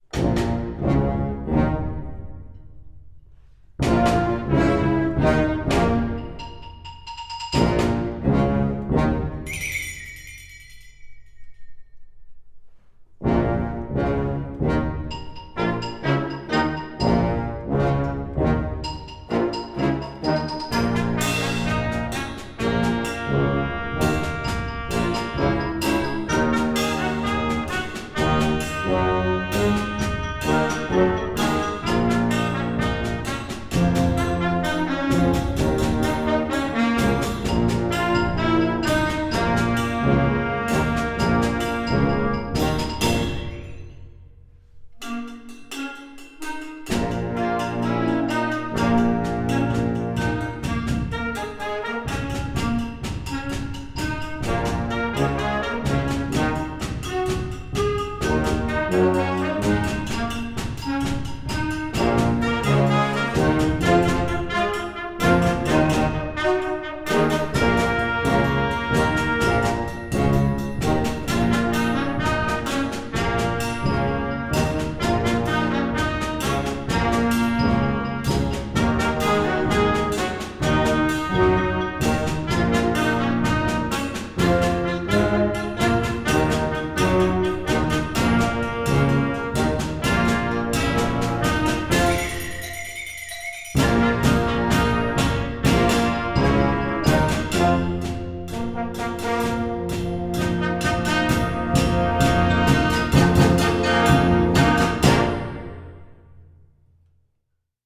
Gattung: Konzertwerk für Jugendblasorchester
Besetzung: Blasorchester
Jazz-Rock-Harmonien und melodische Linien